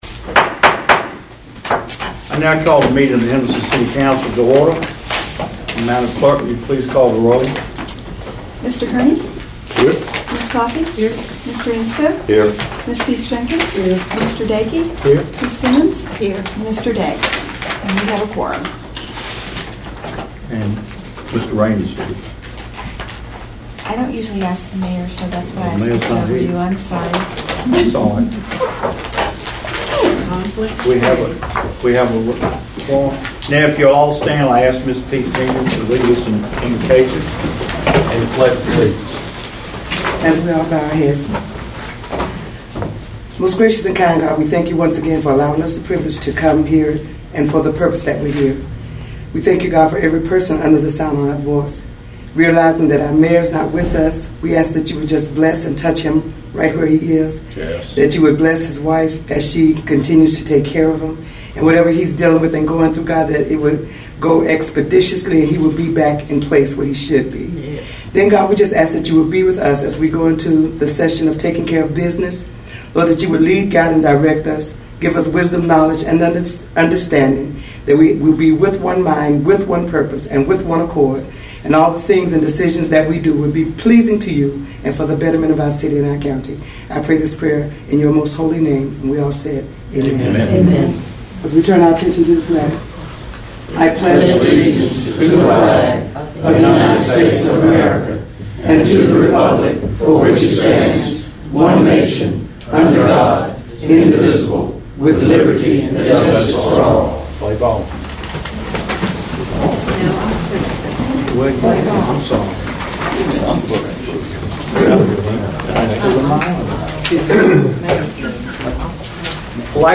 Audio: City of Henderson City Council Meeting January 27th, 2014